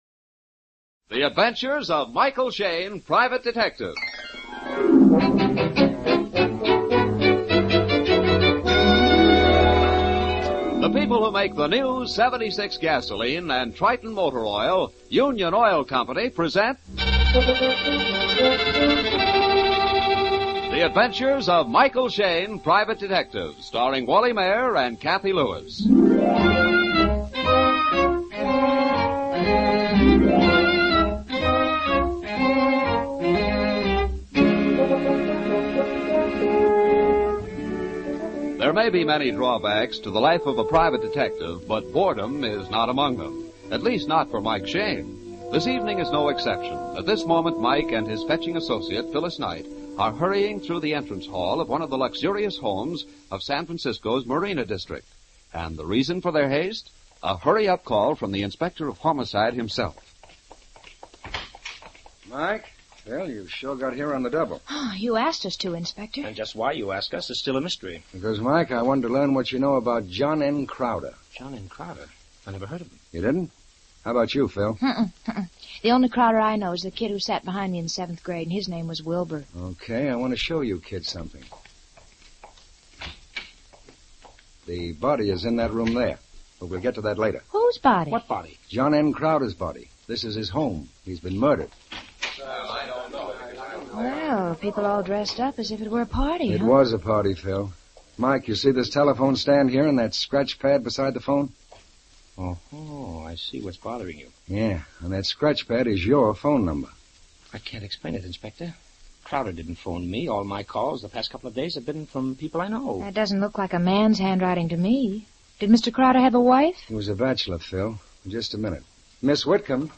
Michael Shayne 450910 John M Crowder s Body, Old Time Radio